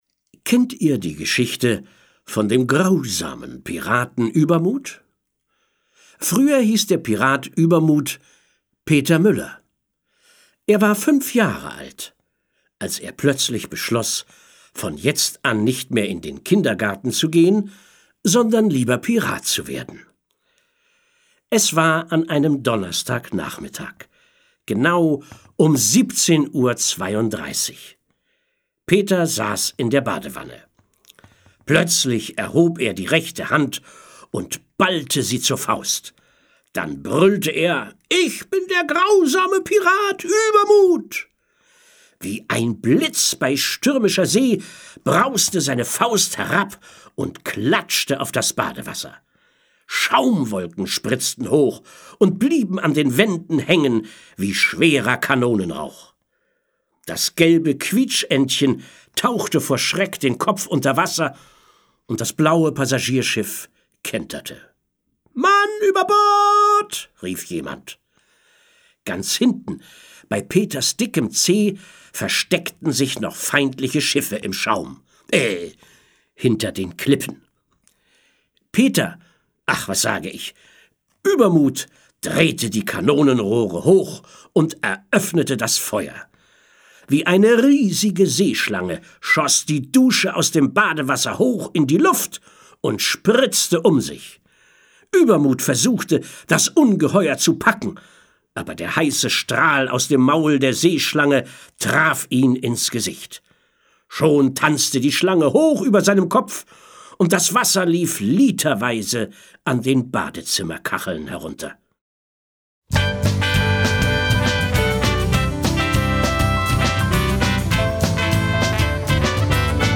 Fröhliche Geschichten, Reime und Lieder zum Groß- und Starkwerden
Gute Laune und gemütliche Stunden sind garantiert mit dieser bunten Mischung aus Geschichten, Liedern und Gedichten.
Kinderlieder